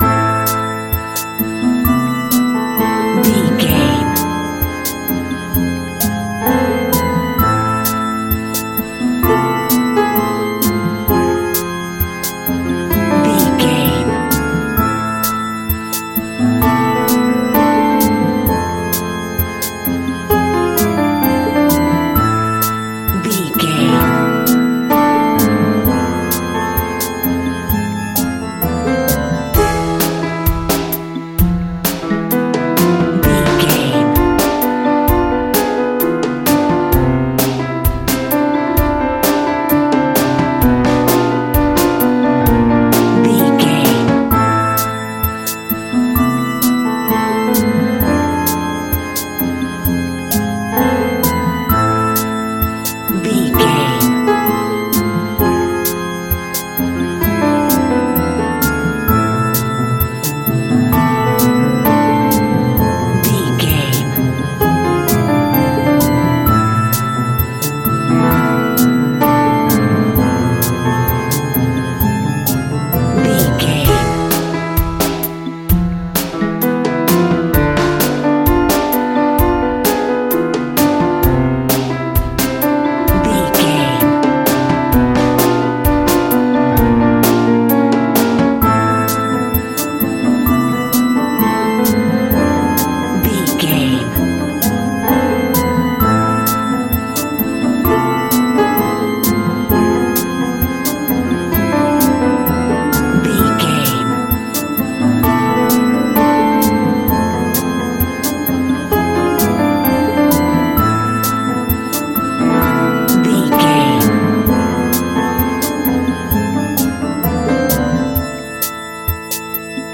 Aeolian/Minor
scary
ominous
dark
eerie
strings
organ
percussion
drum machine
synthesiser
piano
ambience
pads